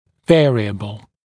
[‘veərɪəbl][‘вэариэбл]изменяемый, переменный, регулируемый; переменная (сущ.)